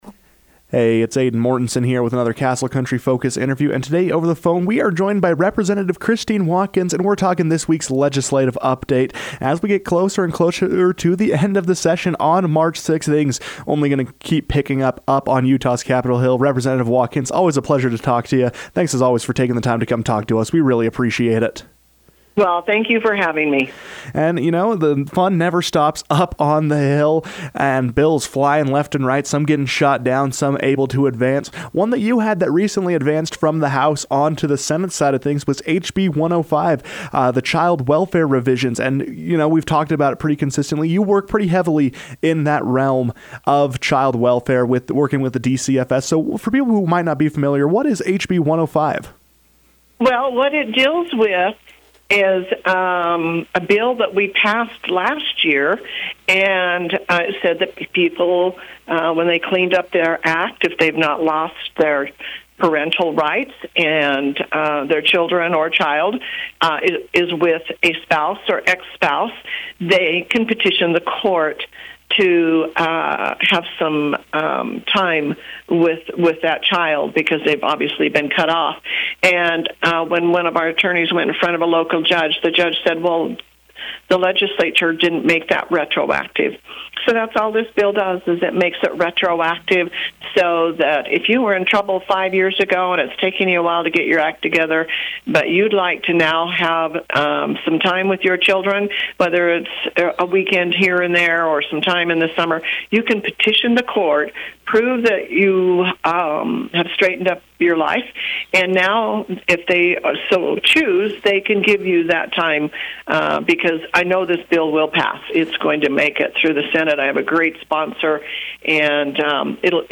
Rep. Watkins joined the KOAL newsroom to provide this week’s legislative update and give a look at what can be expected of the final weeks of the session.